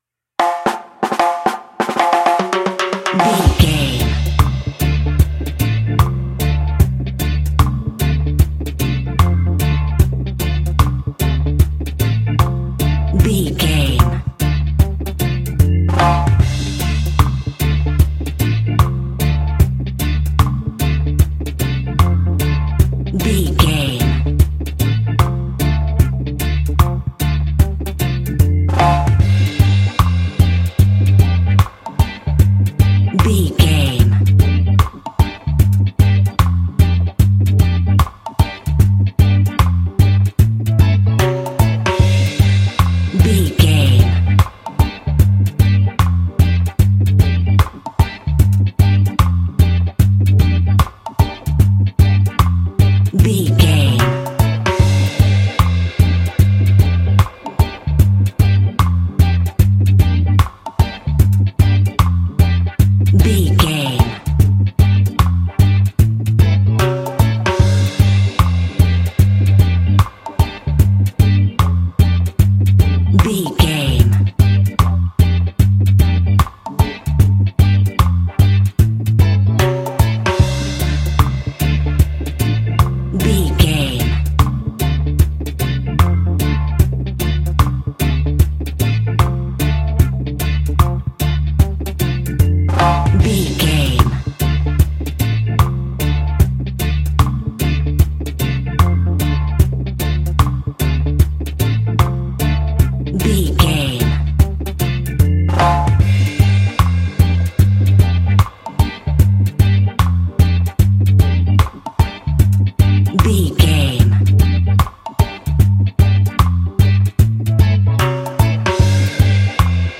Classic reggae music with that skank bounce reggae feeling.
Aeolian/Minor
D
dub
laid back
chilled
off beat
drums
skank guitar
hammond organ
percussion
horns